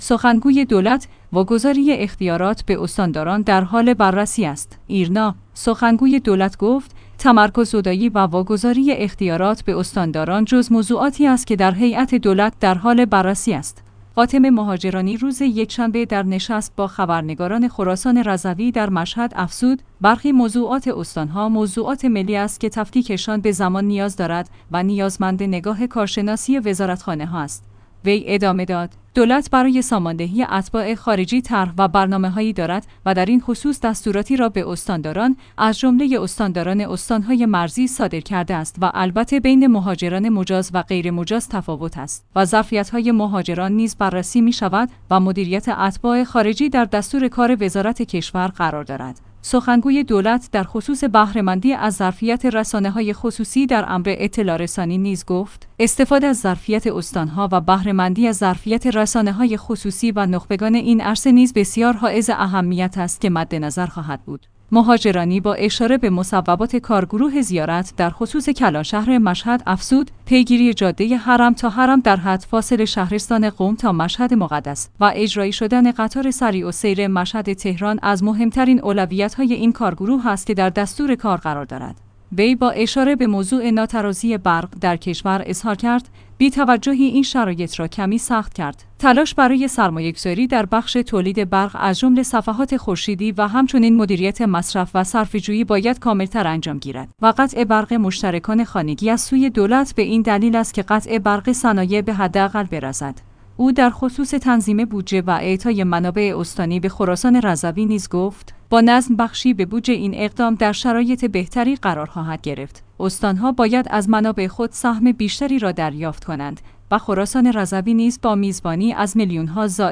فاطمه مهاجرانی روز یکشنبه در نشست با خبرنگاران خراسان رضوی در مشهد افزود: برخی موضوعات استان ها موضوعات ملی است که تفکیکشان به زمان نیاز دارد و نیازمند نگاه کارشناسی وزارتخانه هاست.